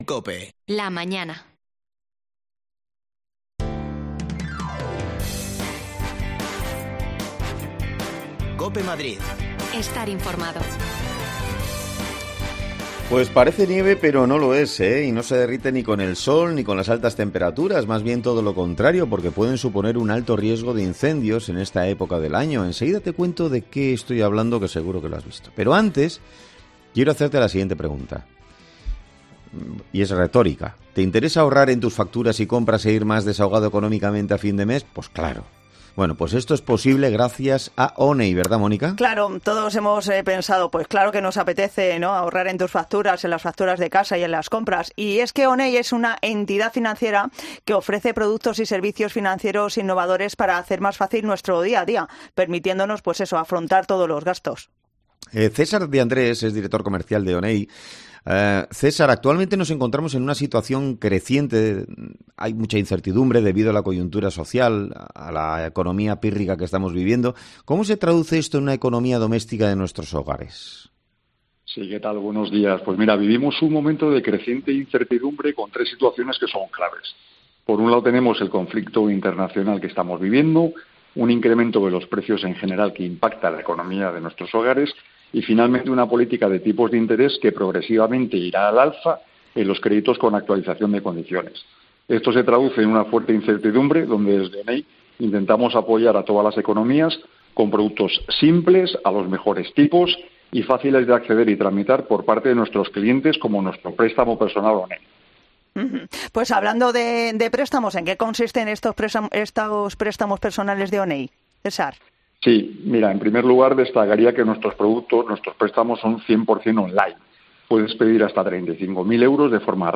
Nos los explican agentes forestales